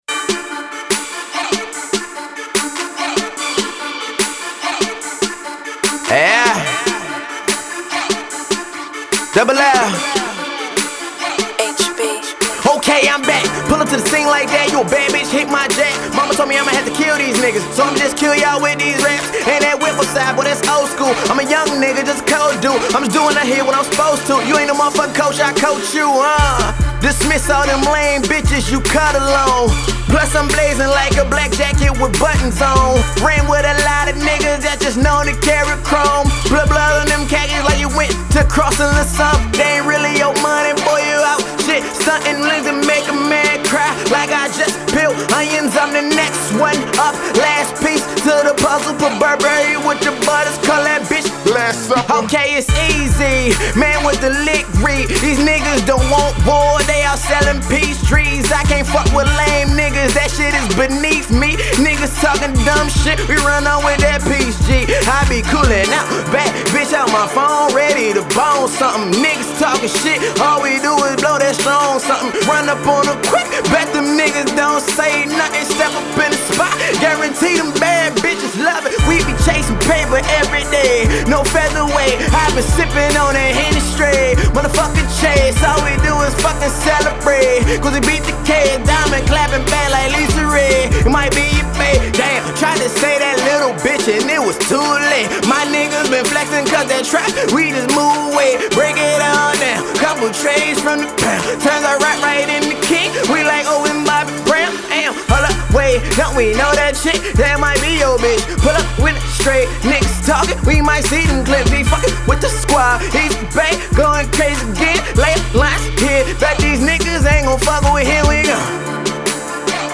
nestled into a more traditional hip-hop motif